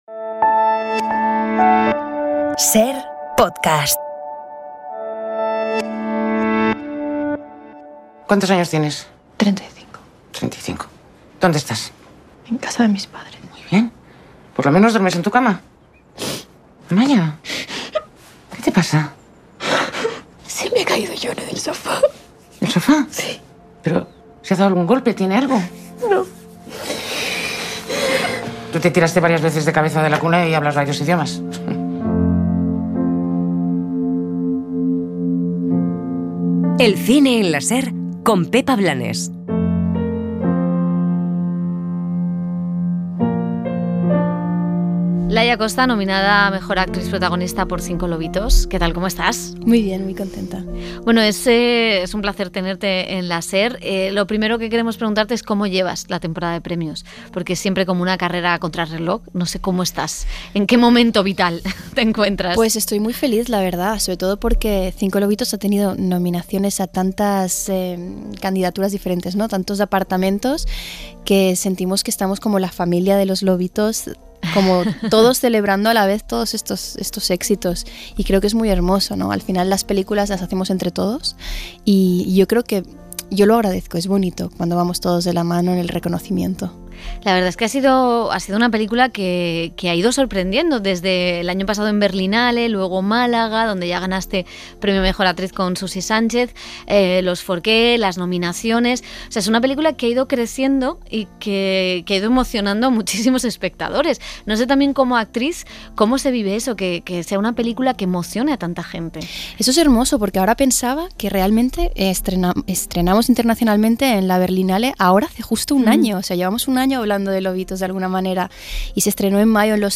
Goyas 2023 | Entrevista a Laia Costa